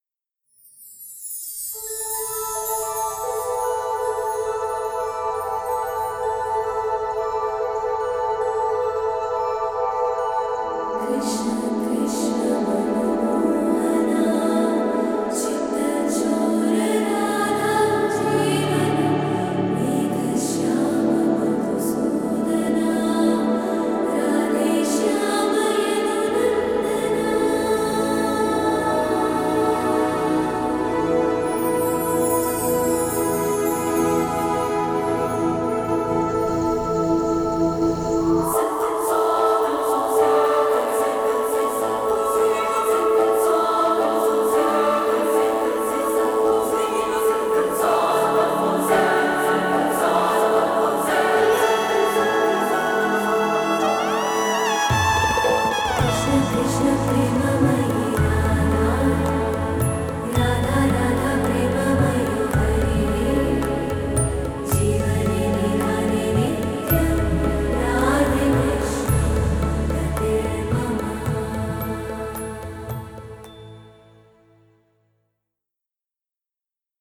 soulful
without disturbing sounds and dialogues.
instrumental music